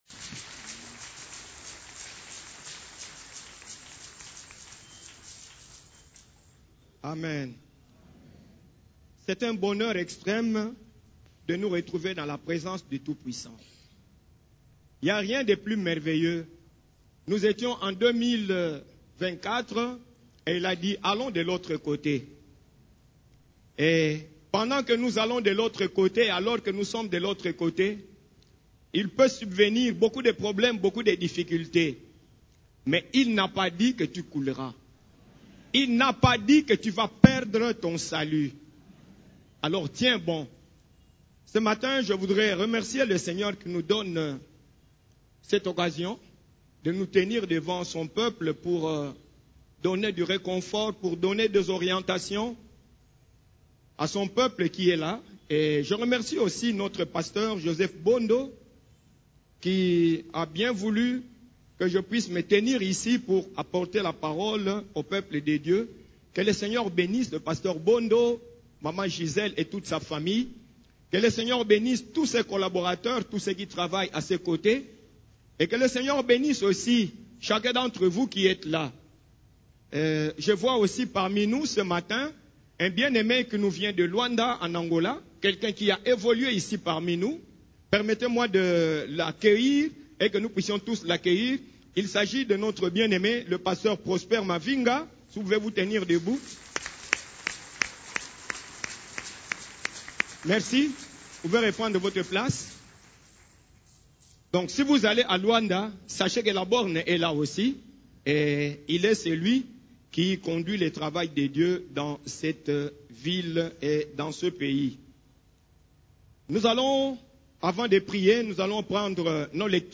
CEF la Borne, Culte du Dimanche, Comment faire face à l'adversité